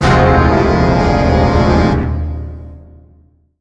1 channel
CTFLostFlag.wav